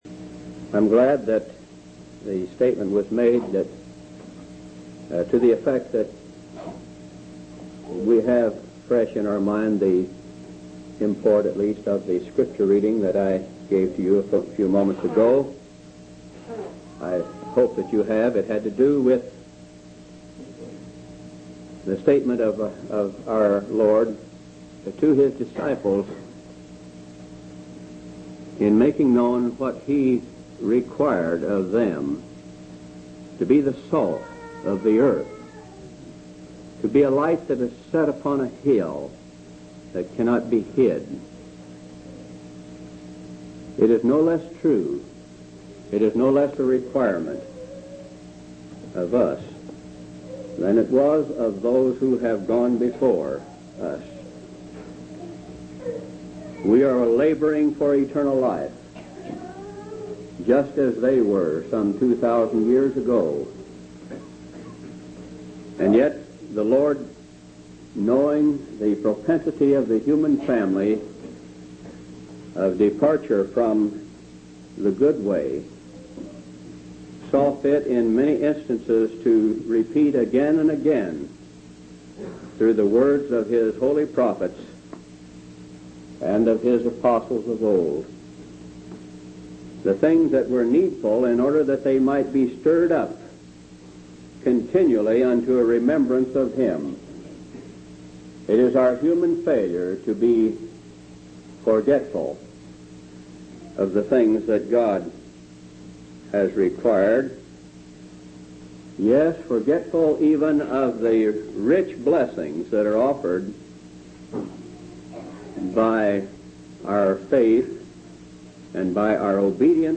11/24/1979 Location: Phoenix Reunion Event: Phoenix Reunion